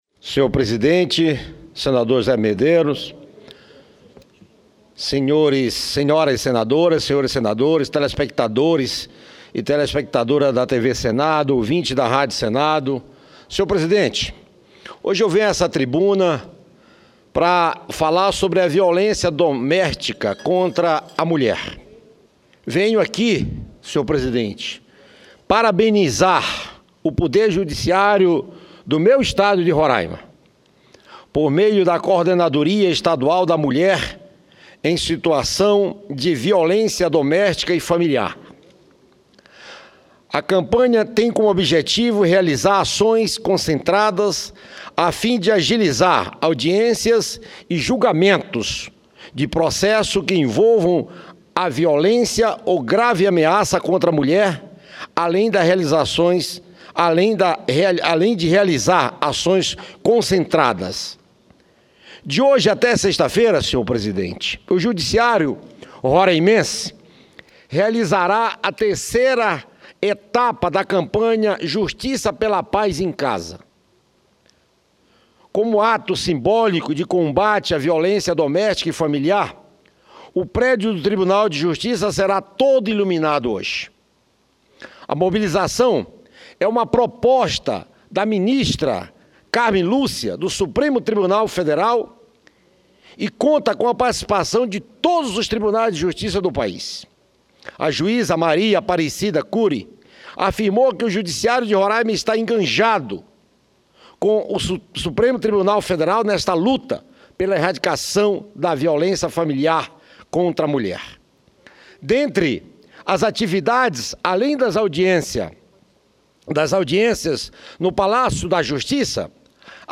Discursos Telmário Mota elogia campanha do Judiciário de Roraima para combater a violência contra mulher RadioAgência Senado 30/11/2015, 15h07 Duração de áudio: 05:04 Tópicos: Judiciário Violência Roraima